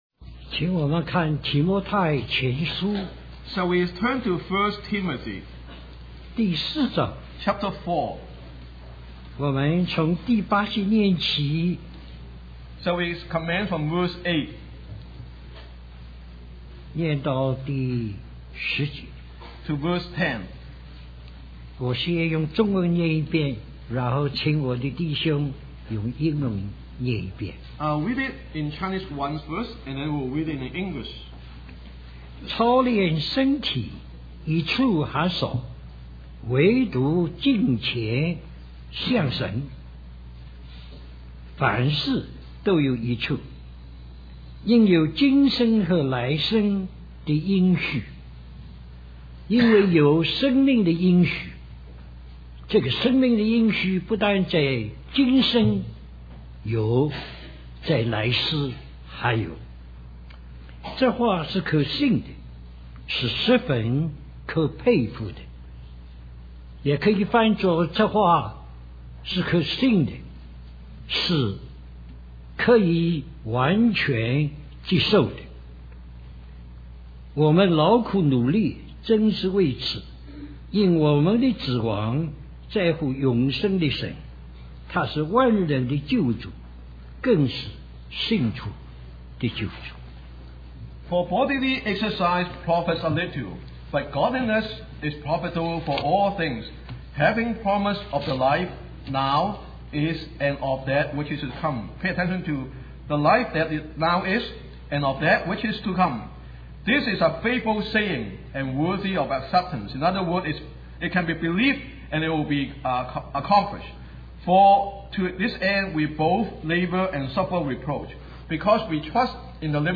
A collection of Christ focused messages published by the Christian Testimony Ministry in Richmond, VA.
Vancouver, British Columbia, CA